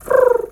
pigeon_2_call_calm_05.wav